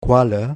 Ääntäminen
IPA : /ˈdʒɛliˌfɪʃ/